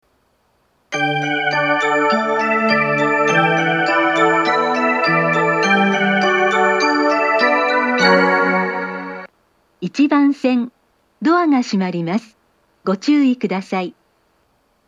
上りは交換がなければ余韻までは鳴りやすいですが、下りは交換がなくても途中切りが多いです。
２０１０年３月以前に放送装置を更新し、発車メロディーに低音ノイズが被るようになりました。
伊東・伊豆高原・伊豆急下田方面   １番線接近放送
１番線発車メロディー